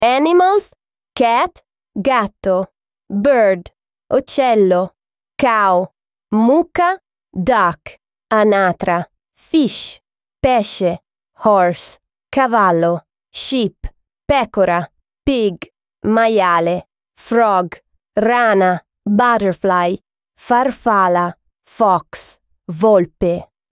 Lesson 6